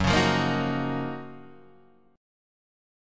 F#M13 chord